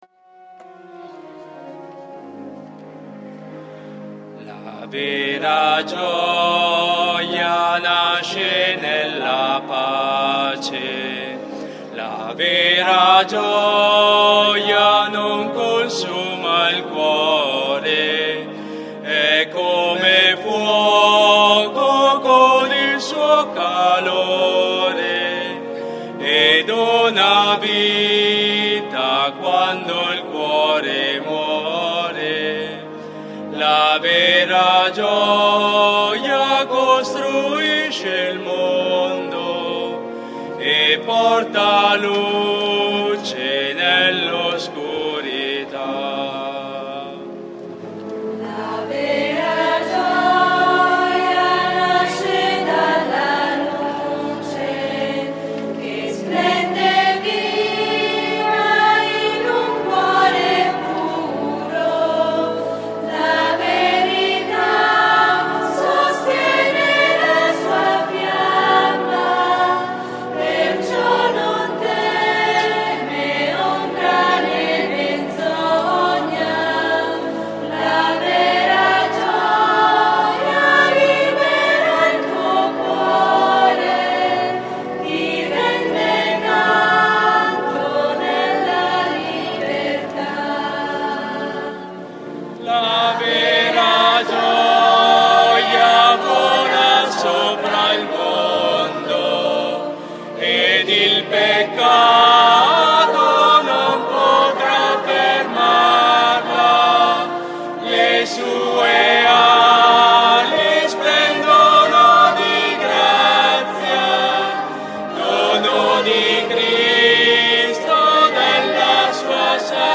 XXIV Dom. Ord. (Festa Patronale - testimonianze)
canto: